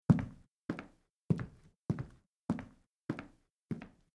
SoundSeed Impact, on the other hand, uses modal synthesis to create variations in the resonance of a base (or "residual") sound.
Sounds generated with SoundSeed Impact
Footsteps on hardwood floor
AK_SS_Impact_Footsteps_Wood_Solid.mp3